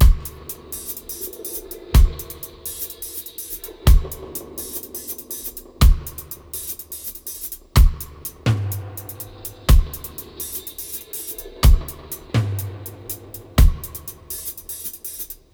121-FX-03.wav